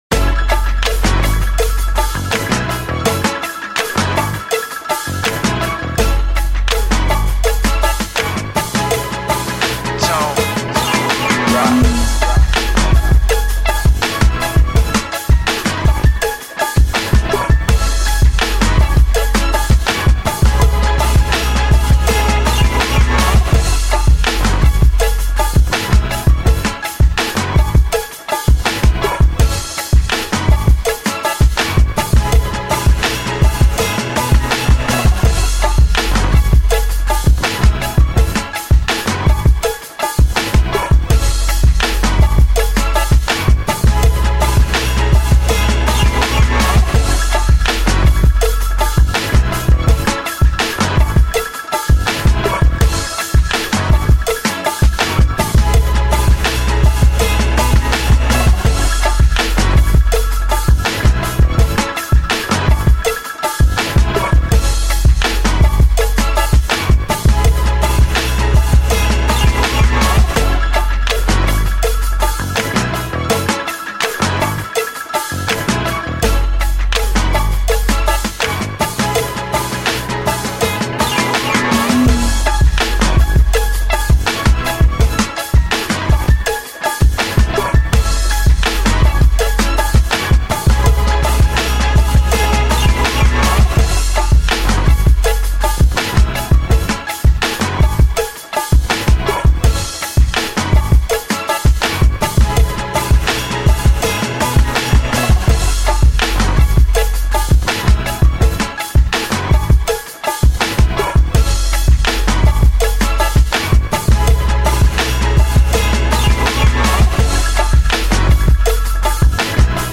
Hip-Hop Instrumental